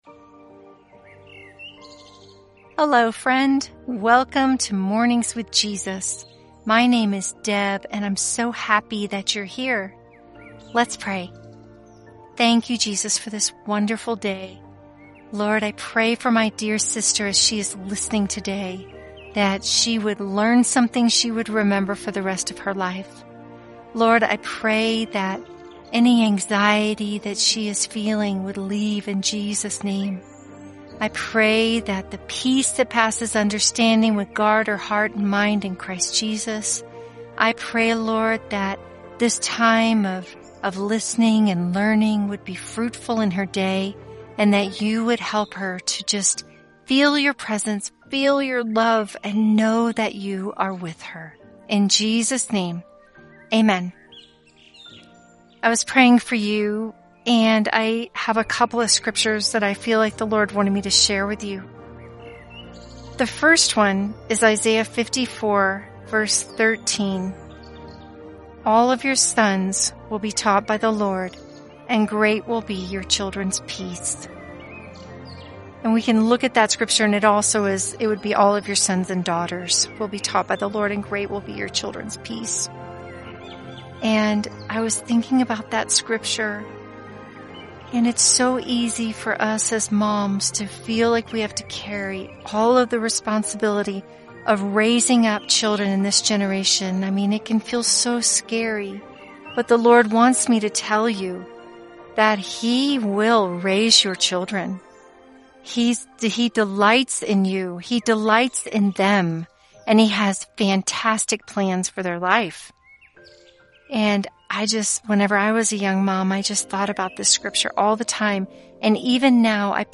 Mornings With Jesus: A Five- Day Audio Devotional Plan for Moms
🌿 Through gentle prayers, Scripture, and Spirit-led encouragement, Mornings with Jesus invites you to slow down, listen, and walk closely with the One who knows you best.